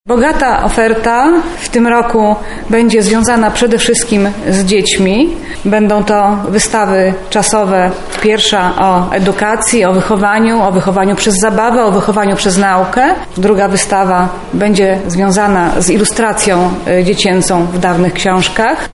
konferencja kozłówka
konferencja-kozłówka.mp3